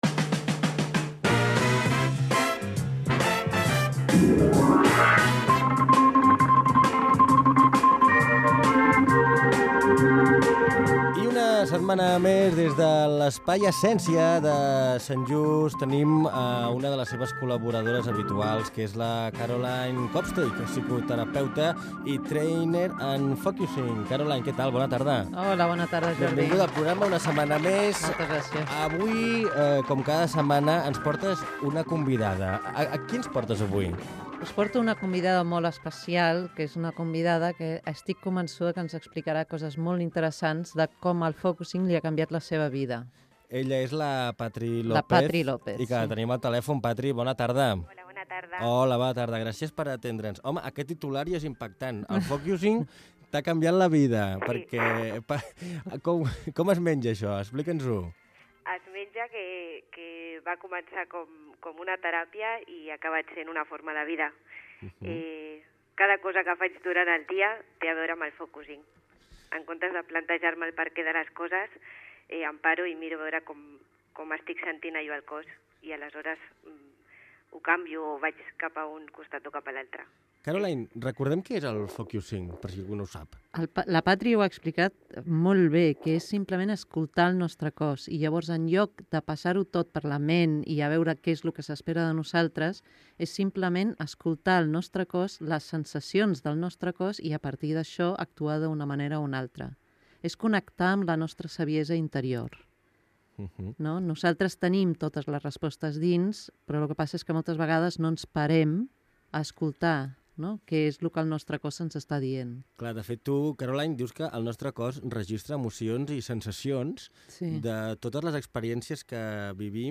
En aquesta entrevista a Radio Desvern parlem en un cas real com travessar les nostres pors amb el focusing